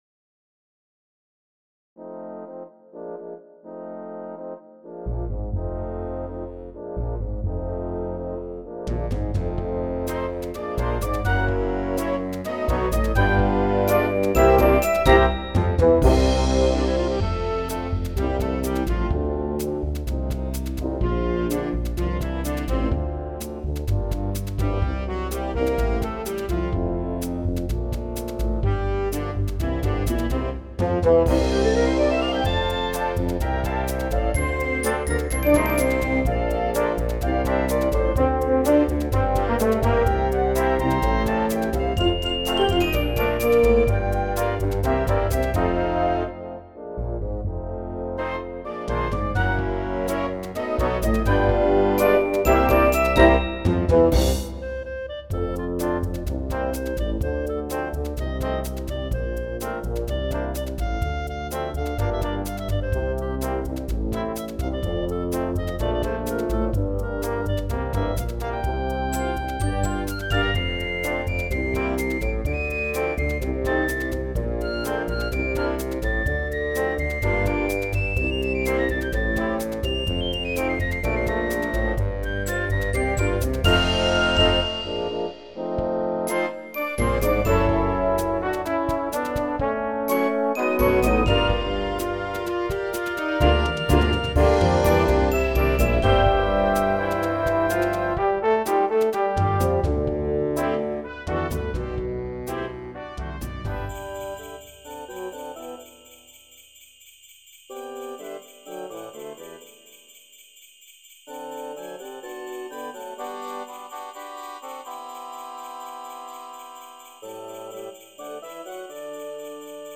Concert Band Edition
in a soul style